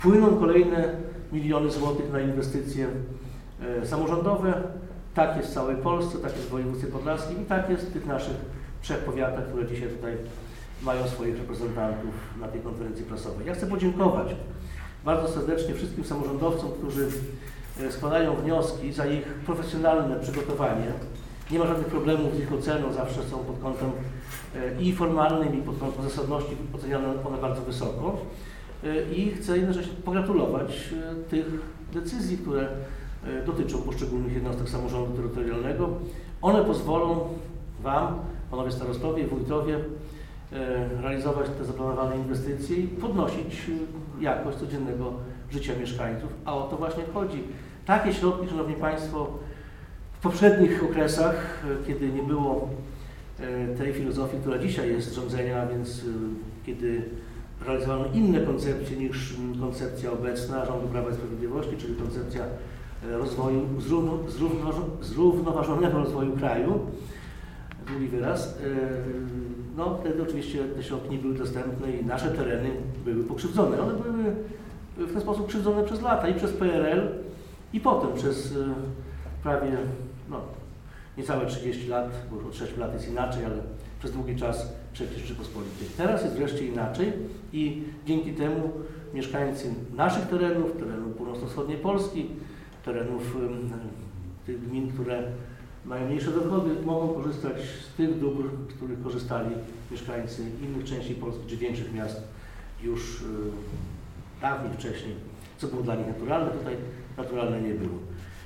We wtorek, 22 lutego w Starostwie Powiatowym w Suwałkach odbyła się konferencja dotycząca projektów, które uzyskały dofinansowanie.
O Rządowym Funduszu Rozwoju Dróg poseł PiS, Jarosław Zieliński: